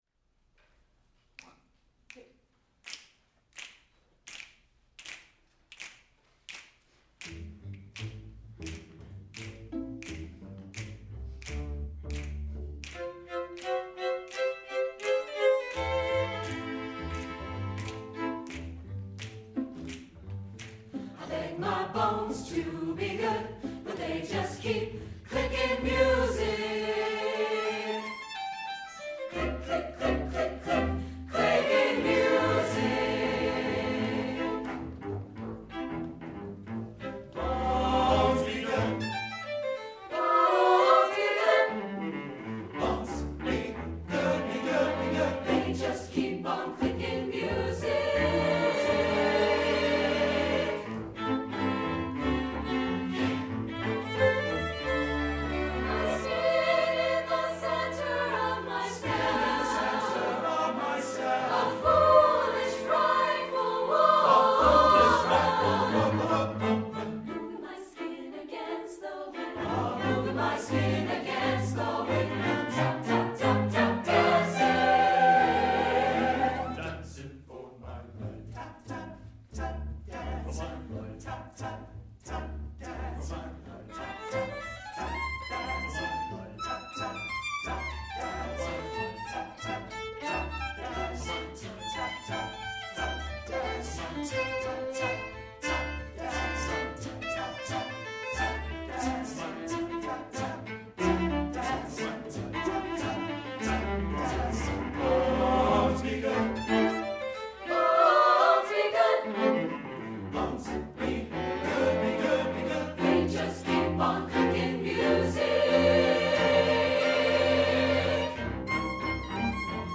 SATB with strings